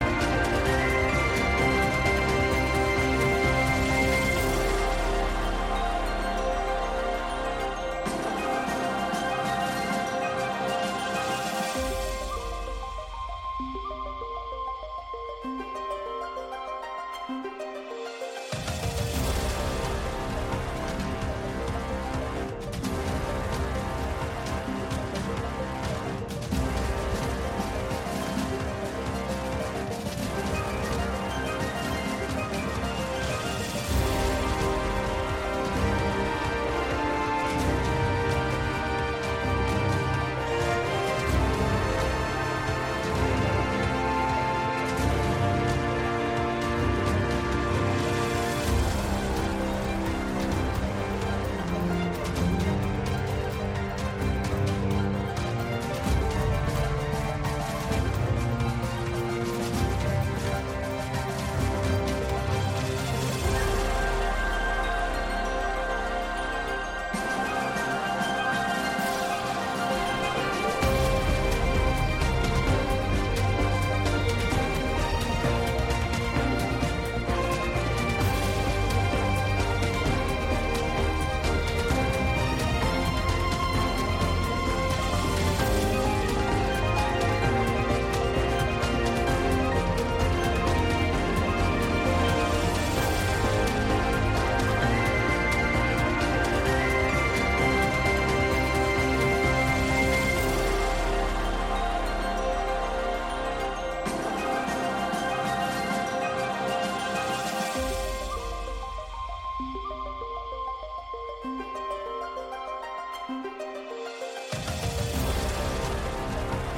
大サビの口笛を高らかに吹き上げるところ好き